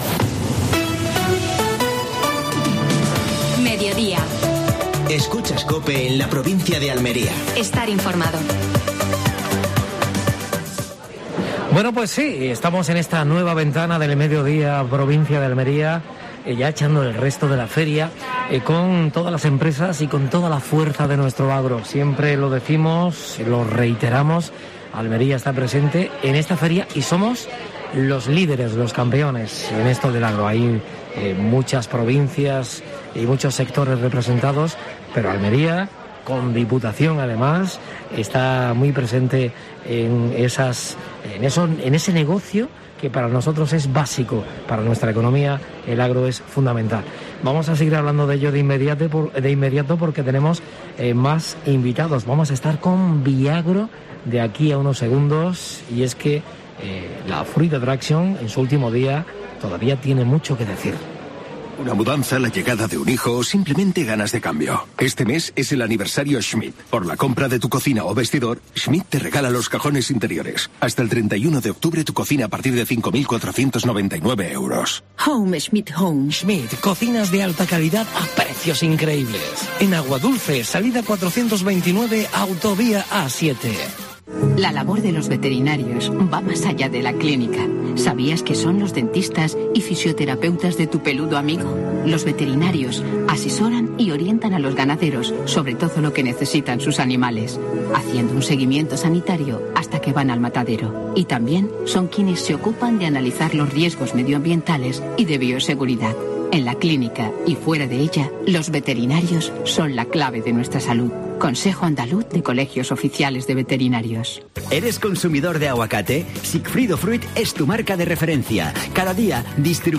Actualidad en Fruit Attraction. Entrevista